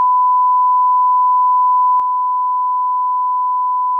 500Hzの音データ/基準音と低減音 [会話音[男性の声]など]